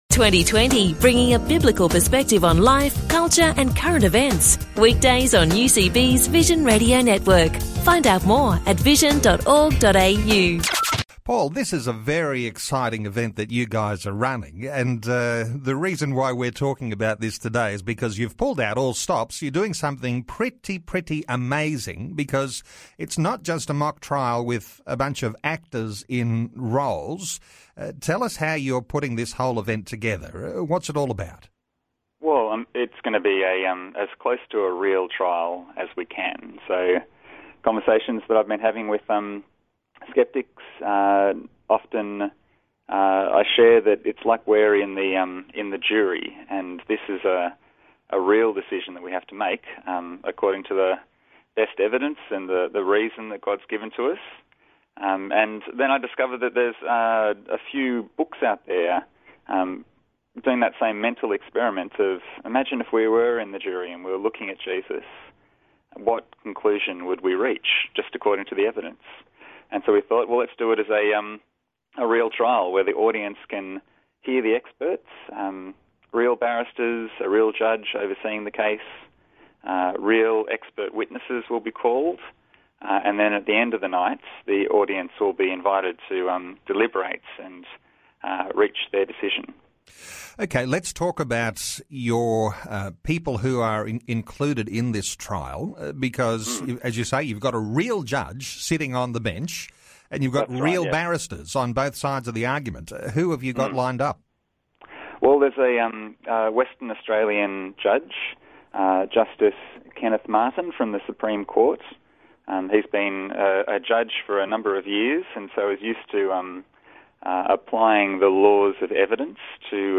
Talk back radio broadcast regarding 'Jesus on trial'